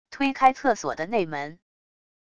推开厕所的内门wav音频